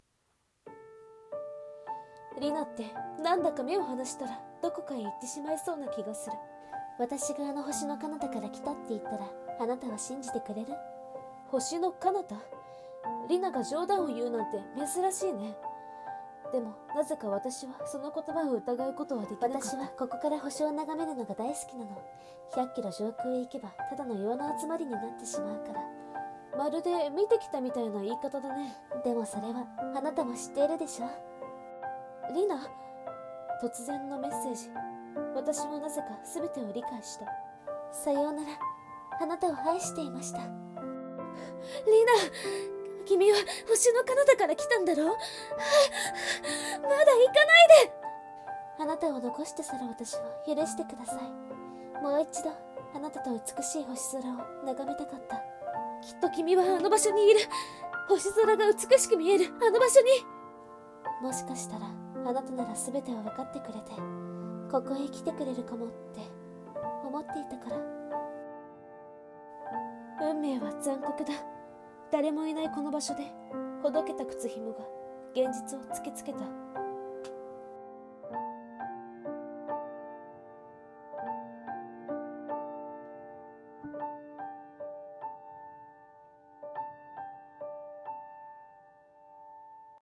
【声劇】星の彼方